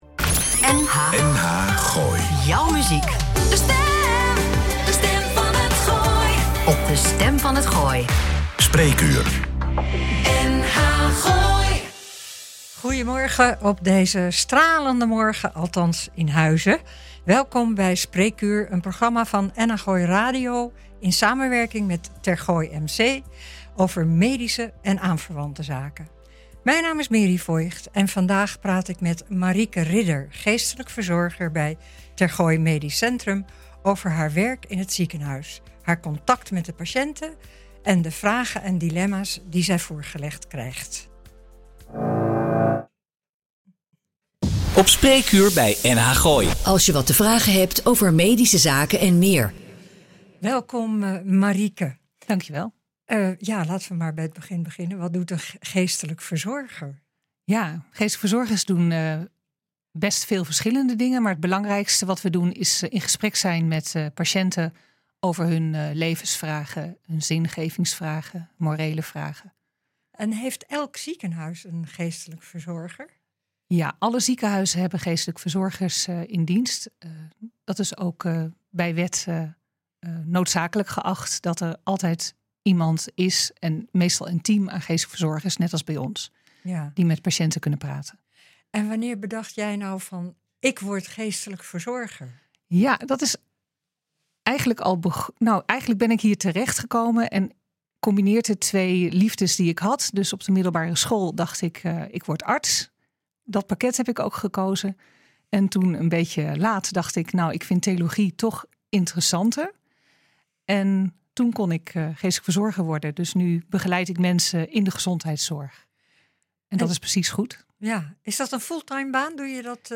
Een verhelderend gesprek